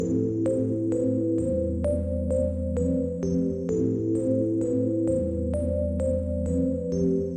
美丽的垫子
描述：美丽的垫子凉爽
标签： 130 bpm Chill Out Loops Pad Loops 1.24 MB wav Key : Unknown
声道立体声